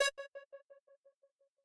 synth1_21.ogg